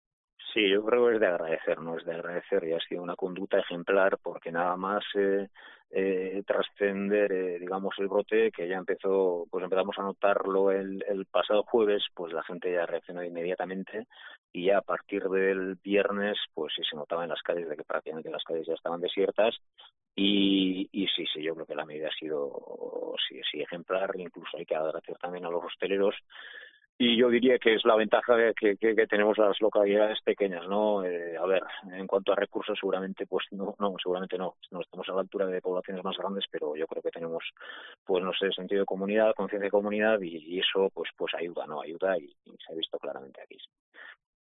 Mikel Arregi, alcalde de Zestoa.